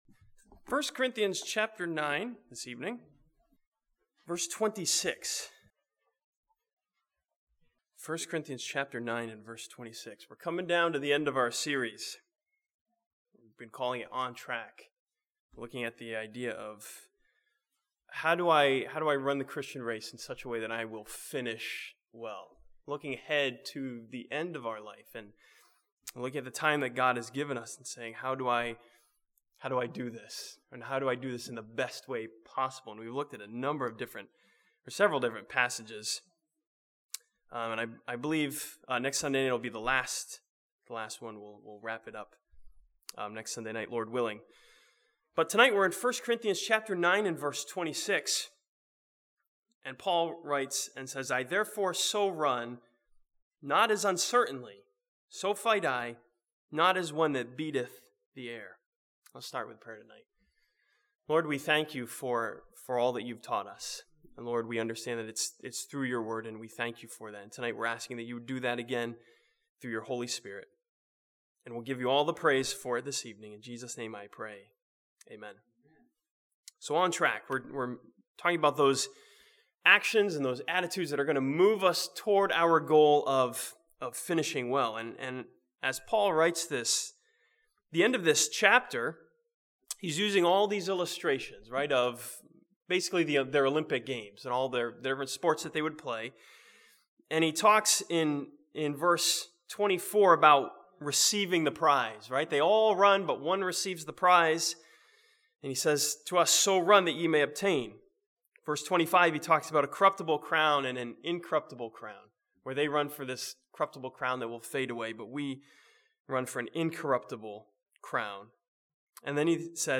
This sermon from 1 Corinthians chapter 9 challenges believers to run the Christian race with efficiency.